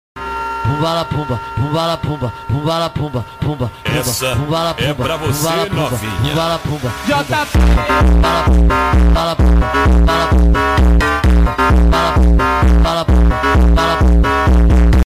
horse walk/cat walk😱😱 sound effects free download